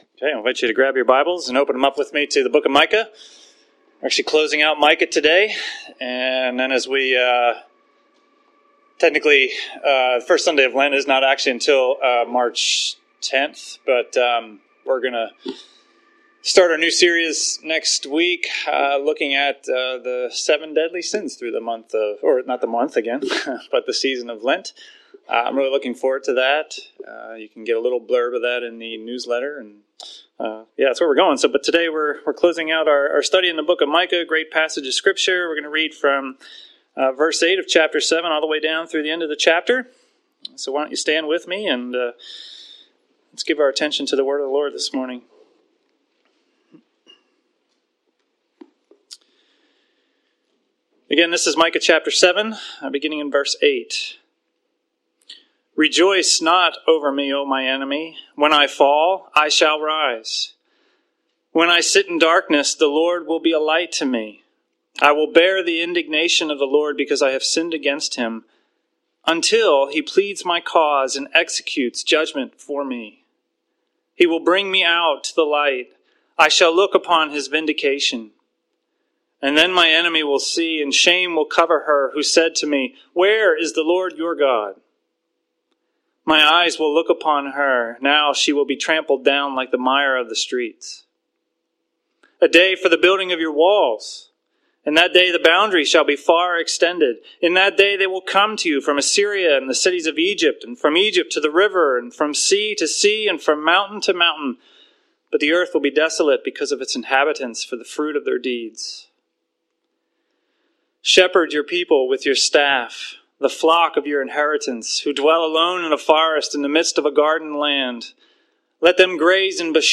Micah 8:7-20” from Sermon February 24